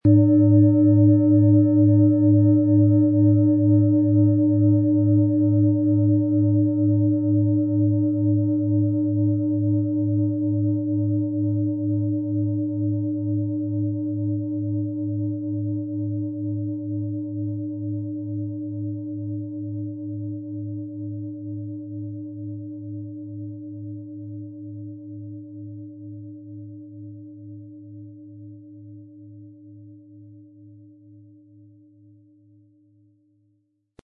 Tibetische Becken-Bauch- und Fuss-Klangschale, Ø 26,1 cm, 1500-1600 Gramm, mit Klöppel
Tibetische Becken-Bauch- und Fuss-Klangschale
HerstellungIn Handarbeit getrieben
MaterialBronze